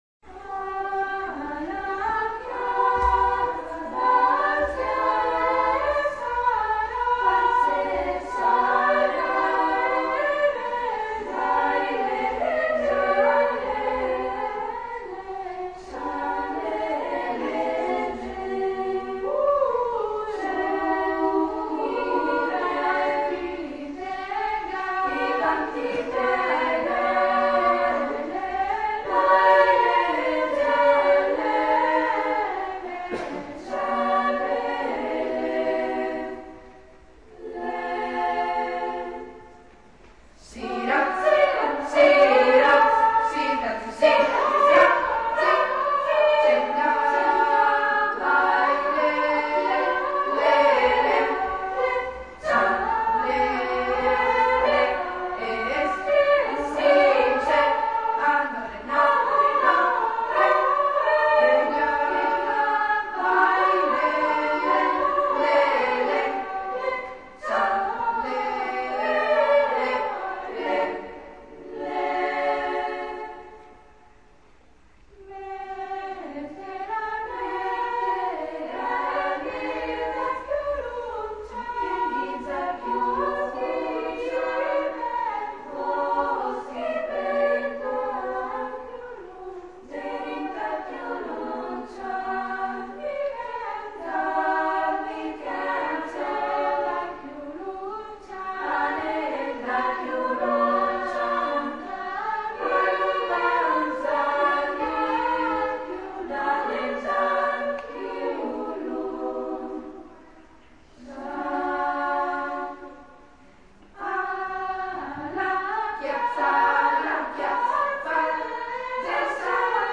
Number of voices: 3vv Voicing: SAA Genre: Secular, Folksong
Language: Armenian Instruments: A cappella